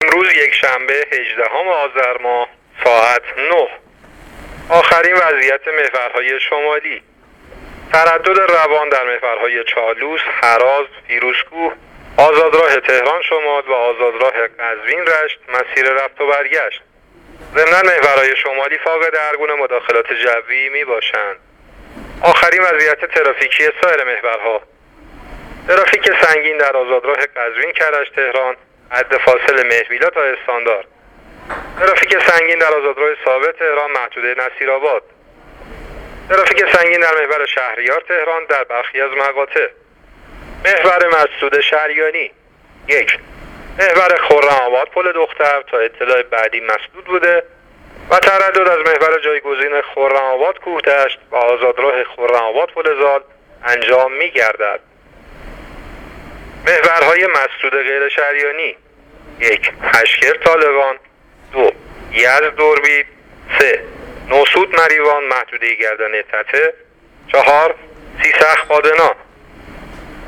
گزارش رادیو اینترنتی از آخرین وضعیت ترافیکی جاده‌ها تا ساعت ۹ هجدهم آذر؛